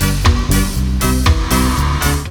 DUBLOOP 07-L.wav